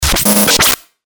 FX-1069-BREAKER
FX-1069-BREAKER.mp3